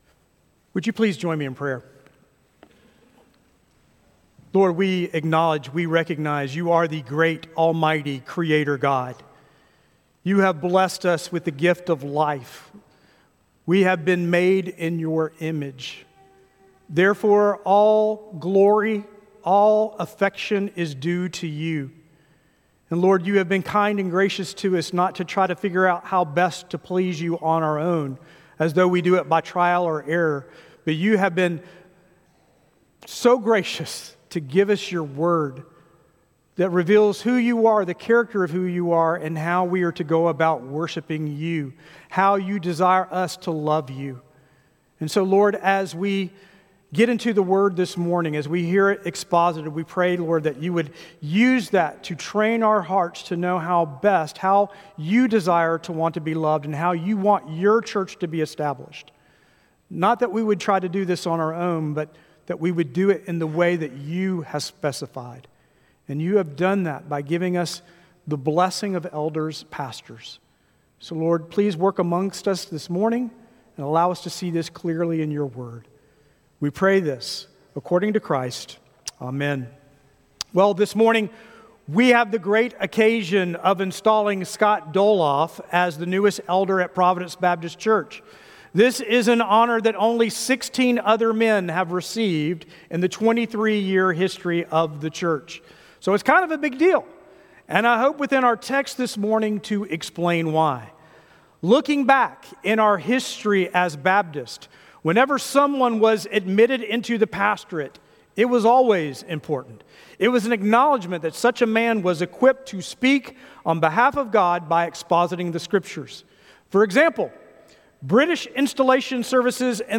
Weekly Sermons from Providence Baptist Church in Huntsville Alabama